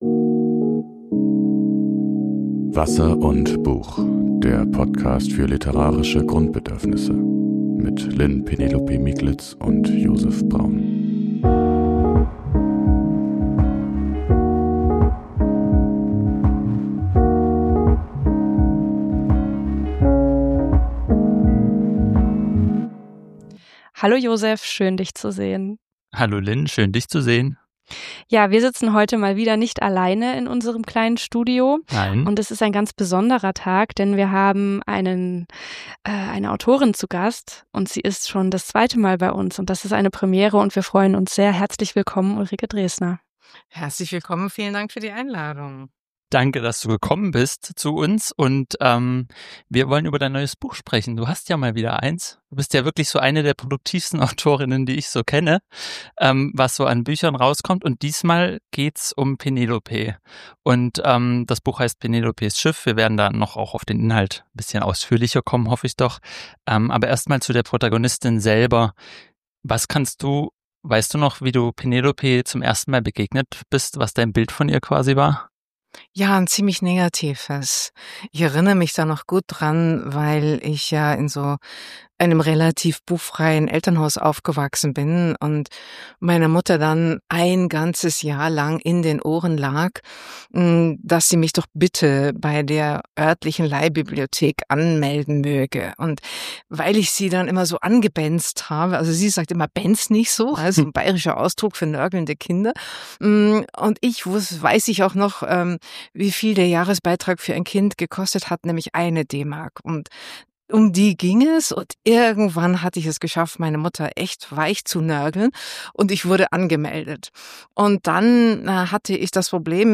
Im Gespräch: Ulrike Draesner ~ Wasser und Buch Podcast